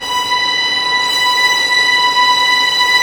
Index of /90_sSampleCDs/Roland L-CD702/VOL-1/STR_Vlns Bow FX/STR_Vls Pont wh%